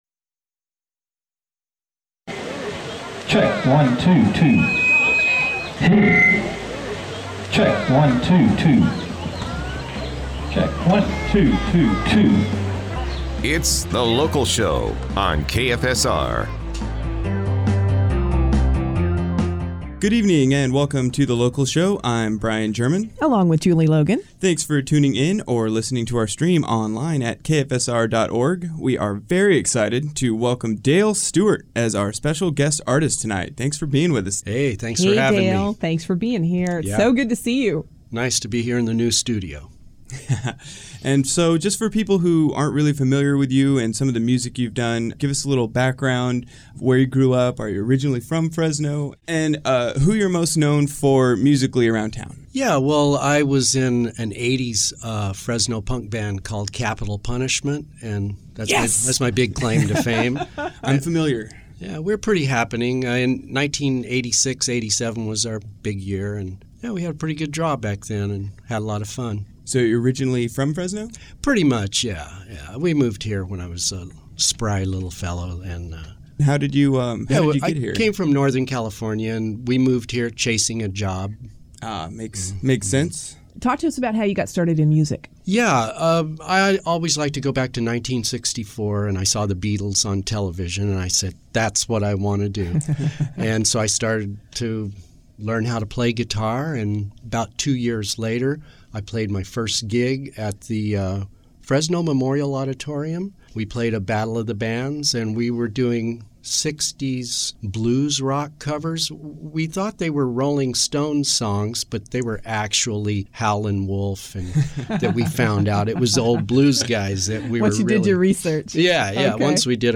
Interview
old Fresno punk scene and plays records and tapes.